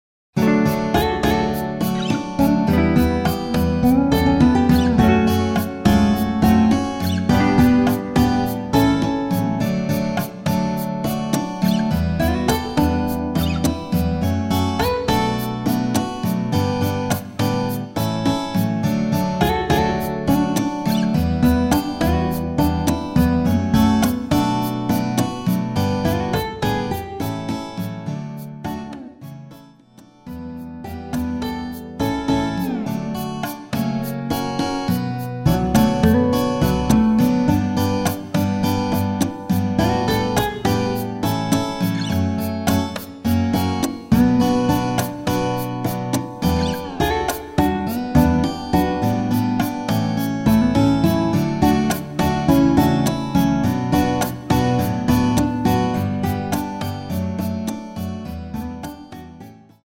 음정은 반음정씩 변하게 되며 노래방도 마찬가지로 반음정씩 변하게 됩니다.
앞부분30초, 뒷부분30초씩 편집해서 올려 드리고 있습니다.